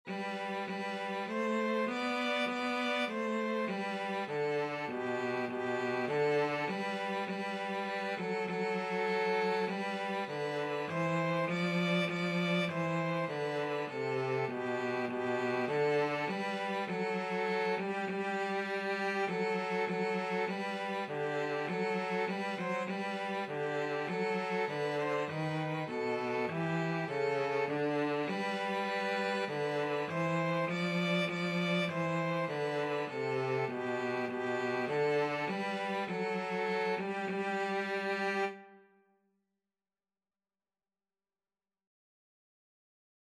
4/4 (View more 4/4 Music)
Violin-Cello Duet  (View more Easy Violin-Cello Duet Music)
Classical (View more Classical Violin-Cello Duet Music)